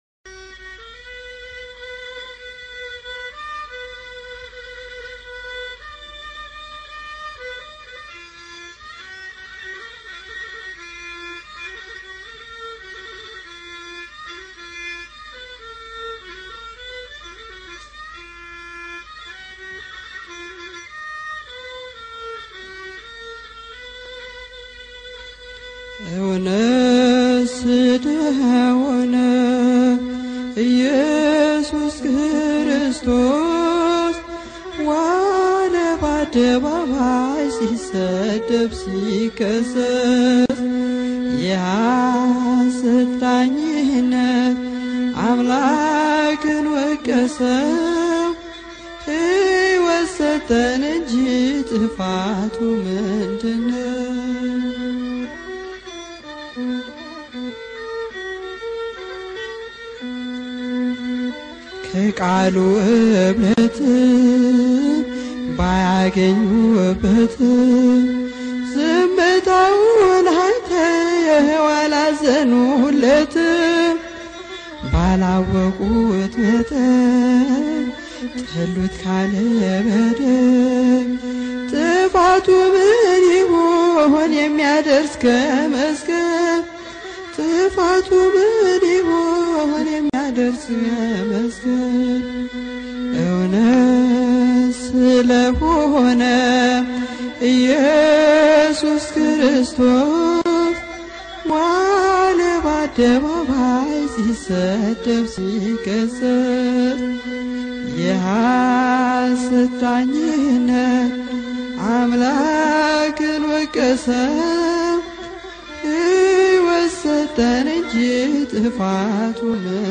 መዝሙር (እውነት ስለሆነ) March 18, 2018